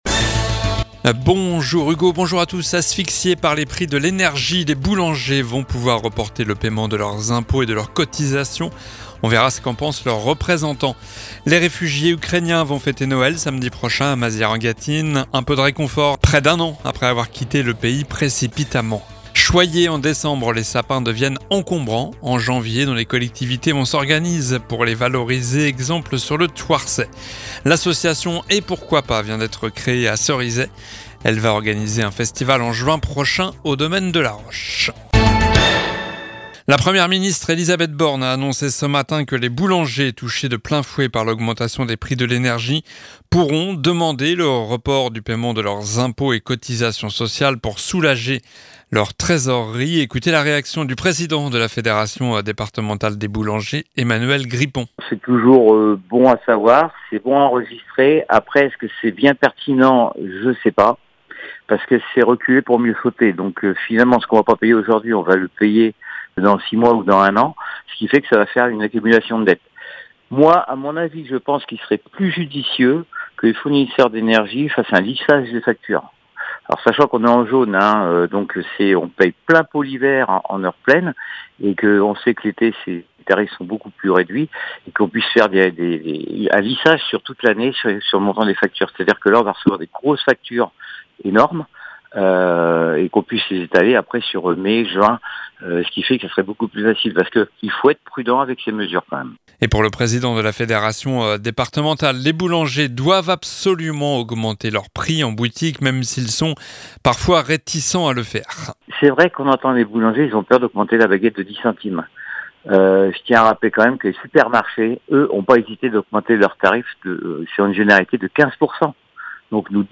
Journal du mardi 03 janvier (midi)